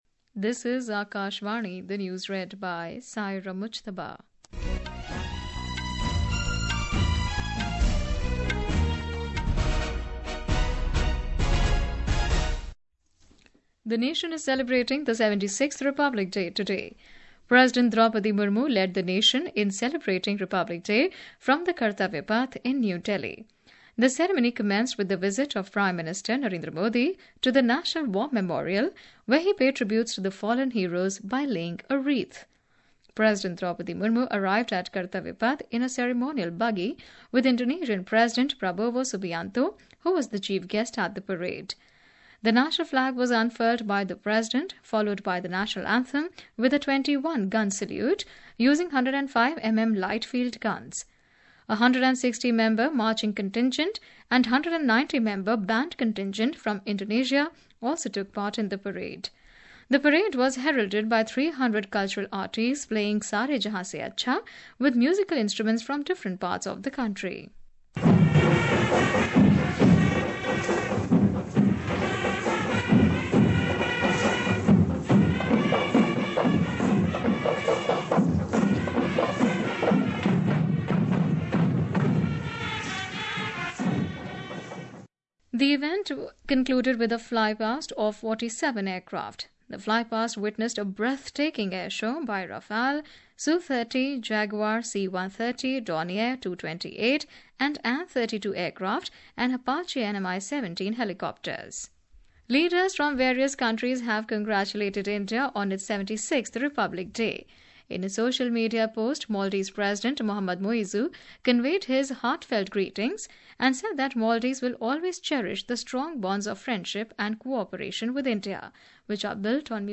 قومی بلیٹنز
Hourly News